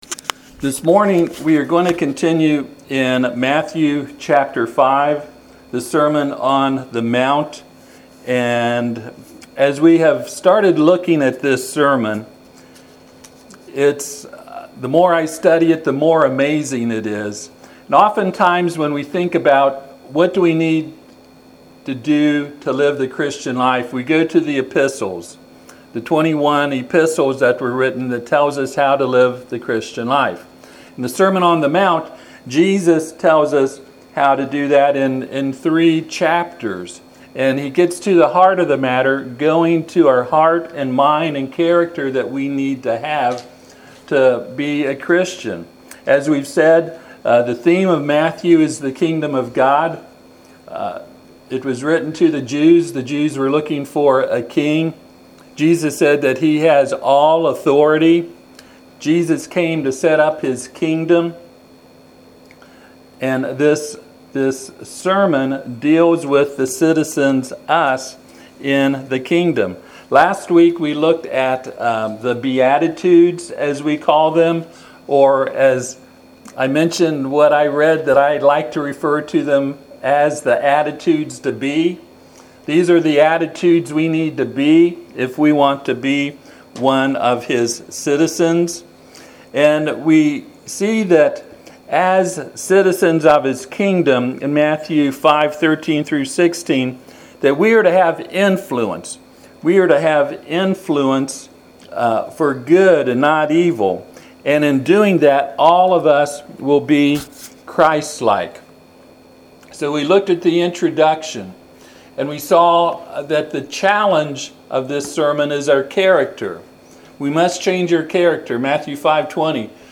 Passage: Matthew 5:13-16 Service Type: Sunday AM